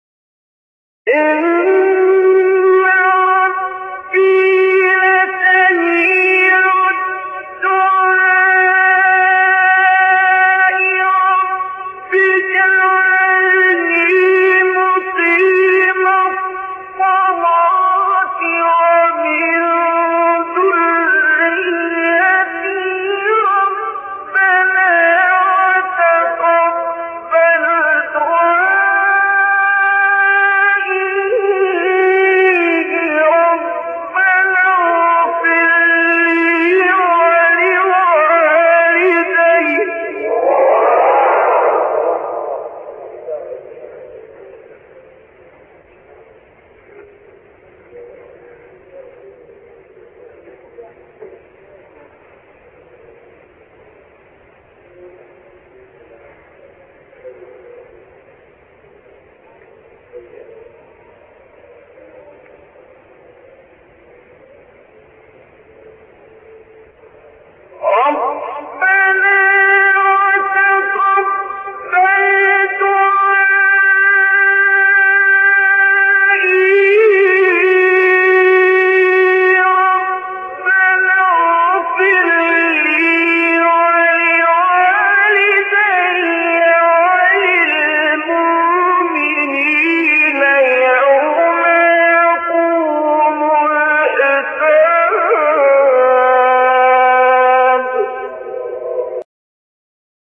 آیه 39-41 سوره ابراهیم عبدالباسط محمد عبدالصمد | نغمات قرآن | دانلود تلاوت قرآن